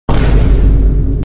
Vine Boom Sound Sound Effect Free Download
Vine Boom Sound